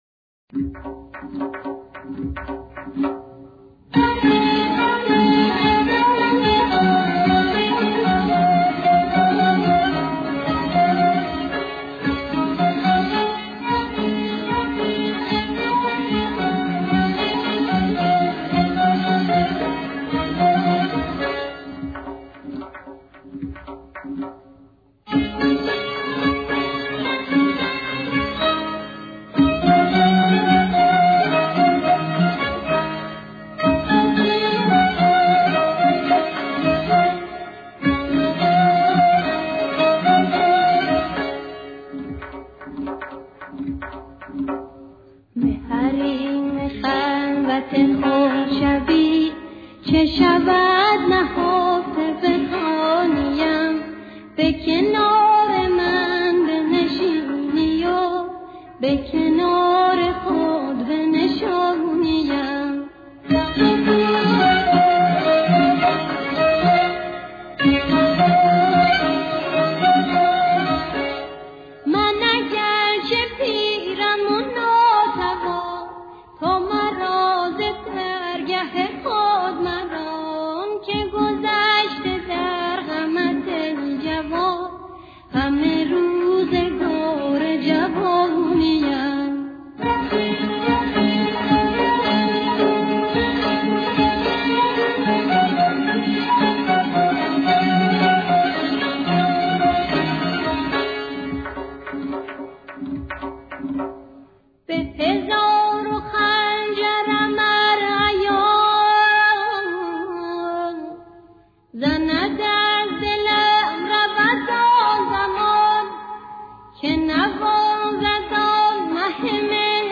آهنگساز: آهنگ قدیمی
دستگاه: ماهور